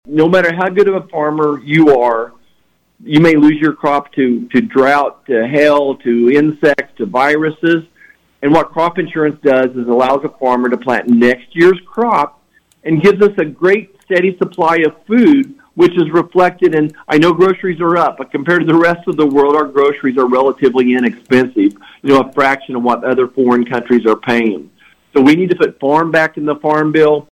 Marshall was a guest on KVOE’s Morning Show on Tuesday.